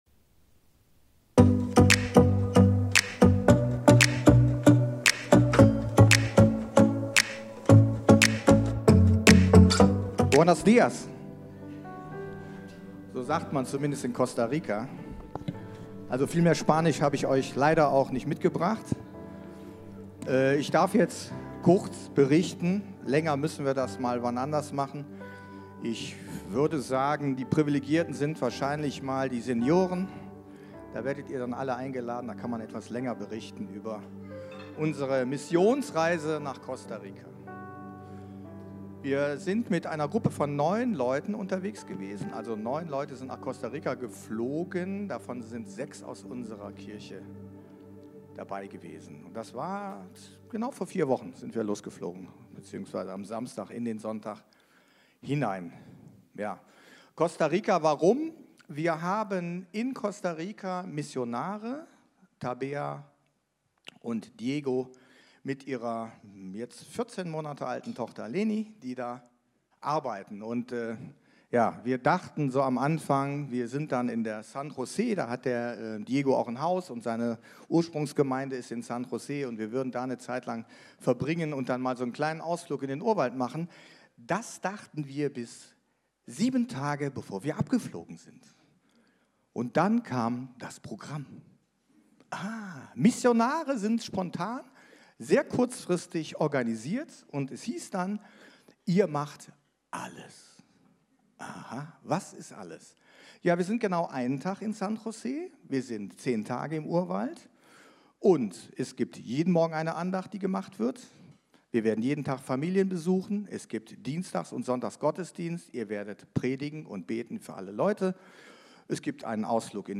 Live-Gottesdienst aus der Life Kirche Langenfeld.
Kategorie: Sonntaggottesdienst